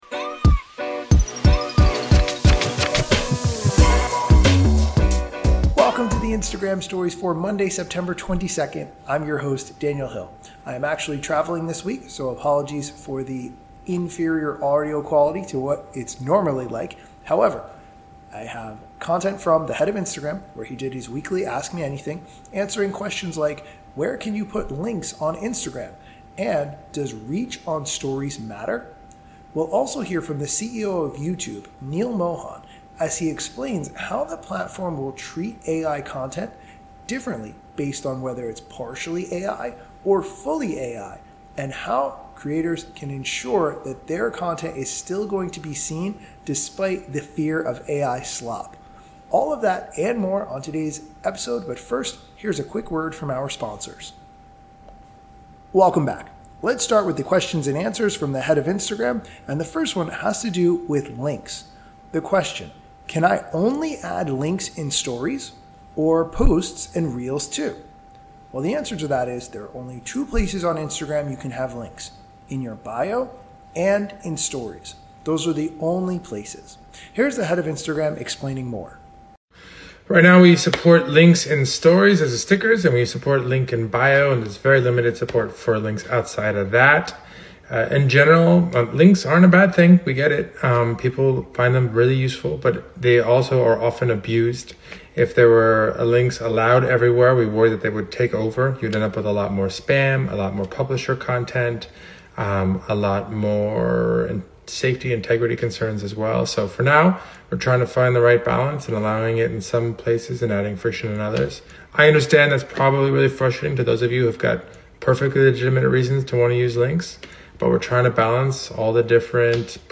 Today's episode features the Head of Instagram, Adam Mosseri, answering questions about why there are links only in Stories and not in captions or Reels, plus he explains how Stories can impact Reach. Additionally we hear from the CEO of YouTube, Neal M. Podcast links by Plink.